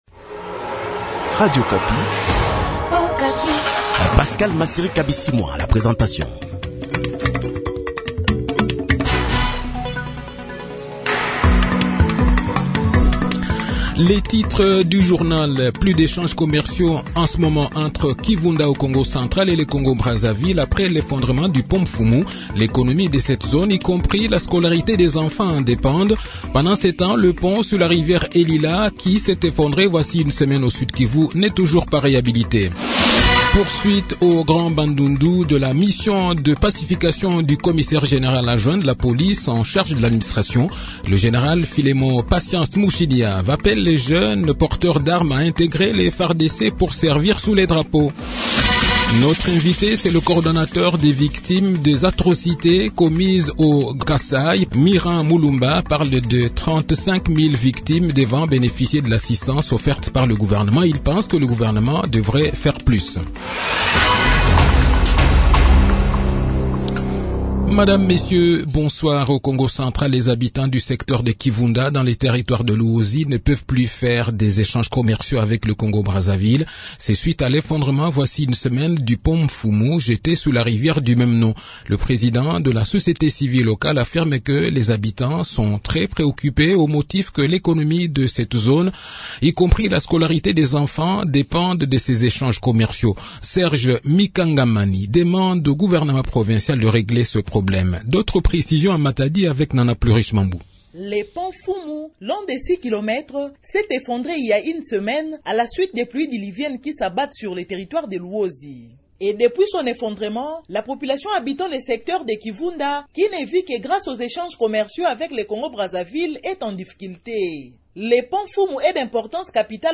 Le journal de 18 h, 9 janvier 2023